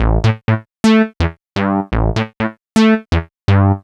cch_bass_loop_silk_125_G.wav